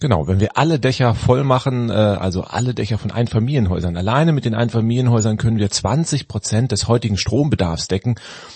schneller_speaker.mp3